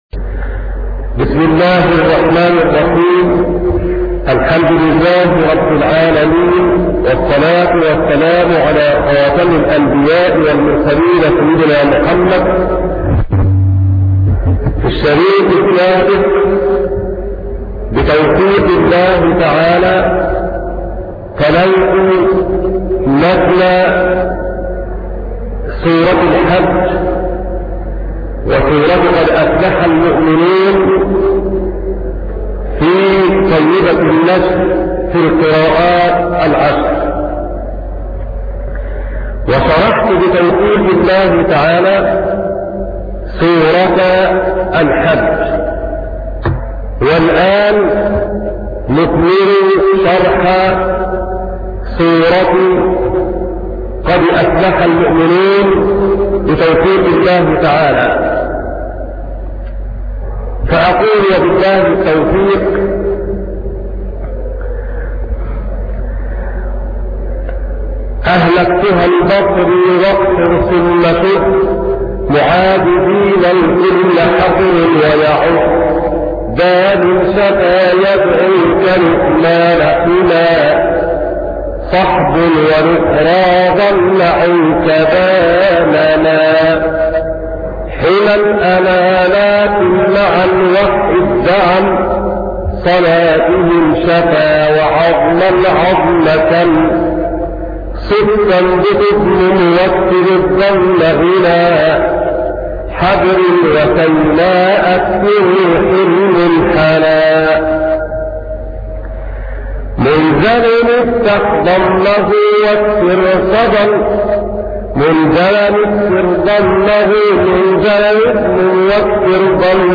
الدرس رقم 36-شرح متن طيبة النشر في القراءات العشر - قسم أغســـــل قلــــبك 2 - الطريق إلى الله